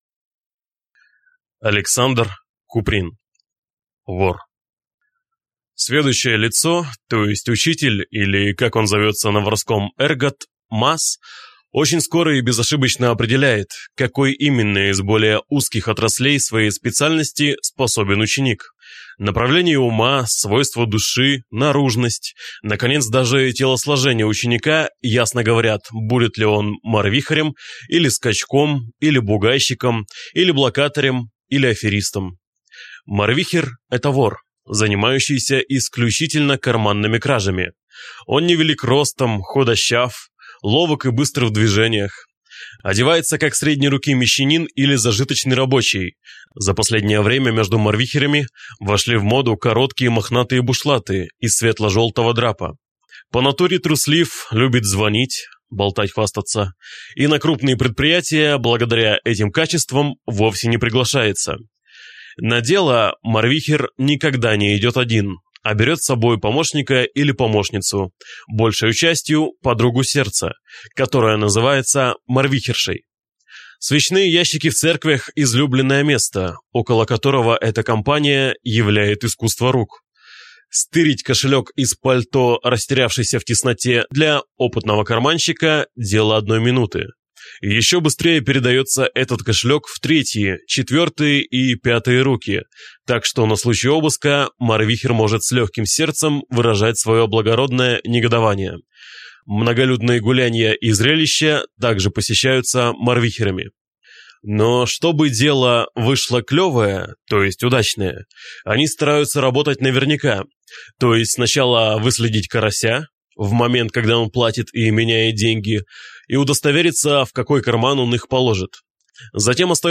Aудиокнига Вор